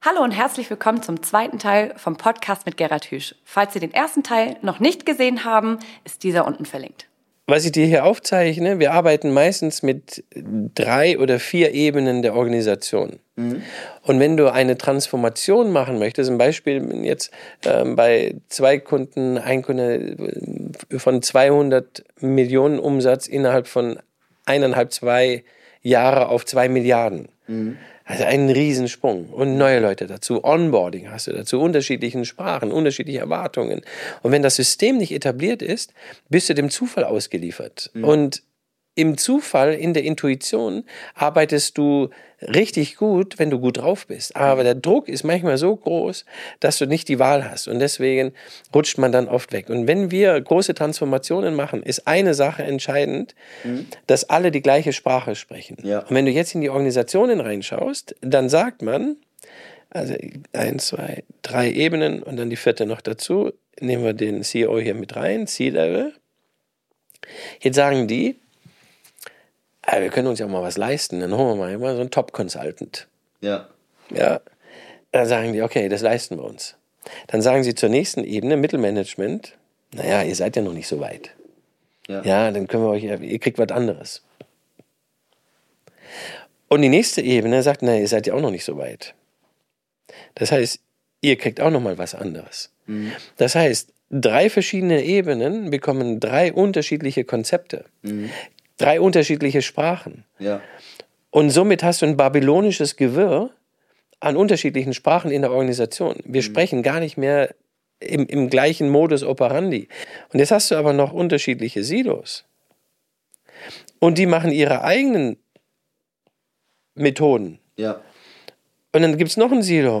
Teil 2 - Die Geheimwaffe für Wachstum im Mittelstand! - Im Gespräch